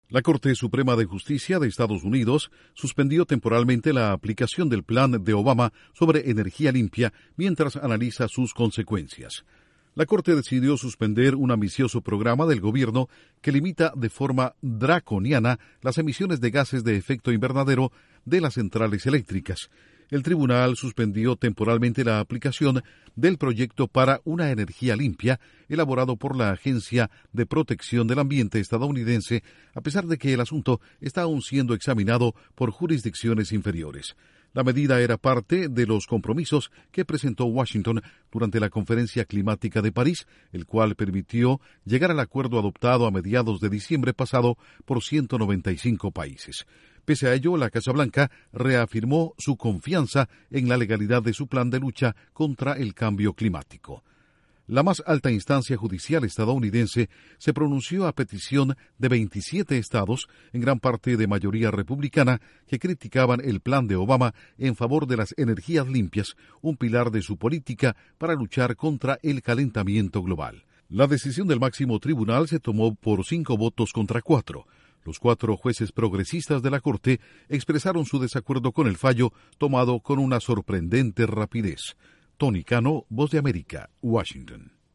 Corte Suprema de Estados Unidos suspende por el momento planes medioambientales de la Casa Blanca. Informa desde la Voz de América en Washington